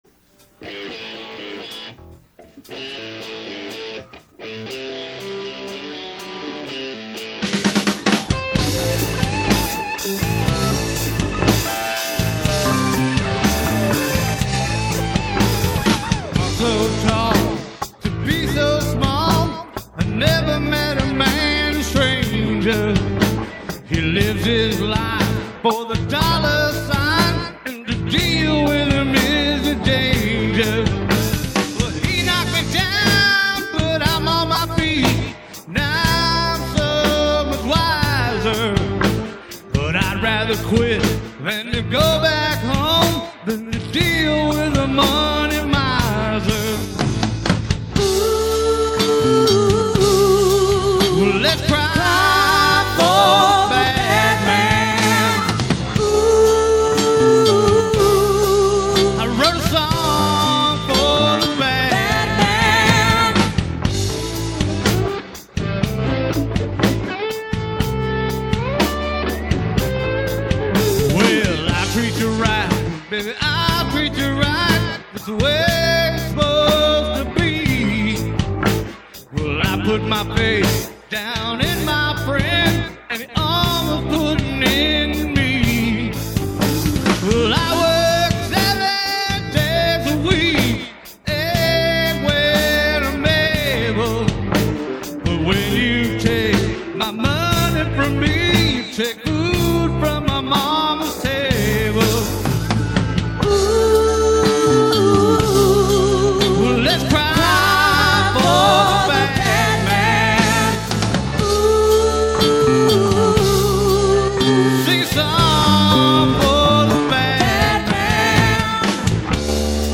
Live mp3